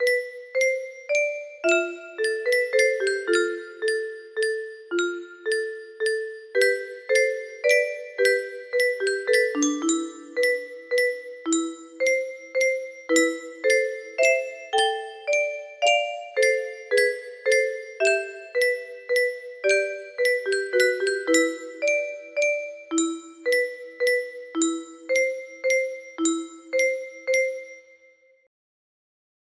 E&C draft music box melody
Full range 60